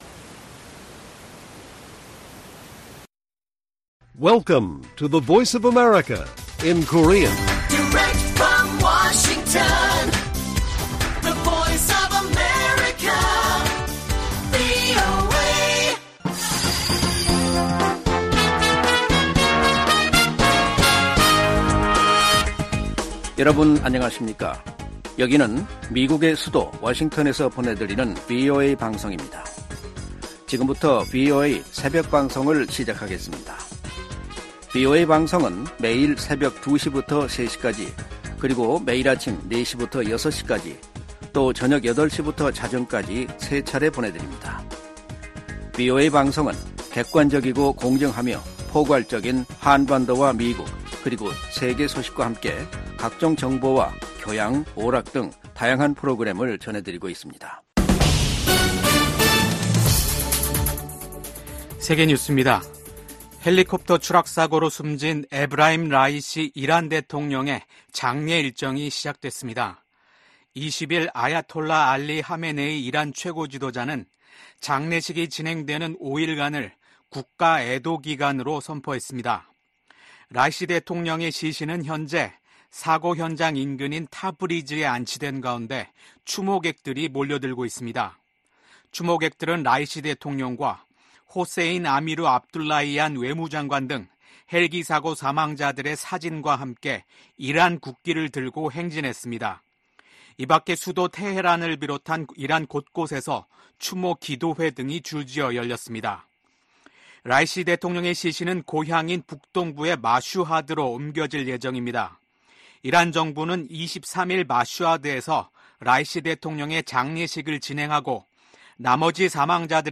VOA 한국어 '출발 뉴스 쇼', 2024년 5월 22일 방송입니다. 최근 북한과 러시아의 협력 강화는 중국도 우려해야 할 사안이라고 미 국무부가 지적했습니다. 미국이 유엔 무대에서 북한과 러시아 간 불법 무기 이전을 비판하면서, 서방의 우크라이나 지원을 겨냥한 러시아의 반발을 일축했습니다. 유럽연합 EU는 러시아가 중국과의 정상회담 후 북한 옹호성명을 발표한 데 대해 기회주의적인 선택이라고 비판했습니다.